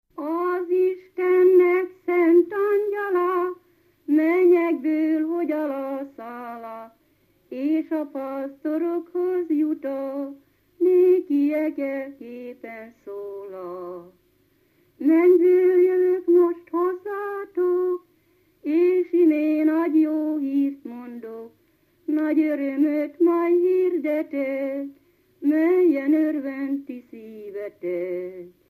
Felföld - Abaúj-Torna vm. - Selyeb
ének
Stílus: 2. Ereszkedő dúr dallamok
Szótagszám: 8.8.8.8
Kadencia: 8 (3) 5 1